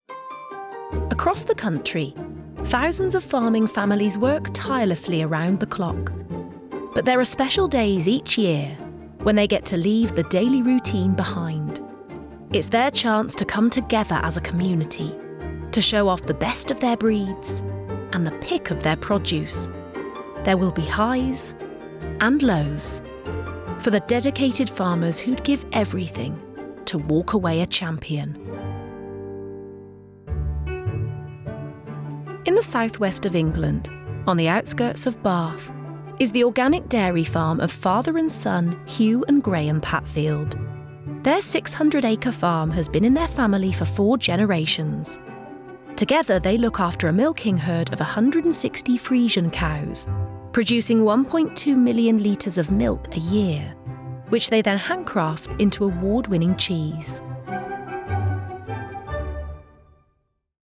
• Native Accent: Hereford, West Country
• Home Studio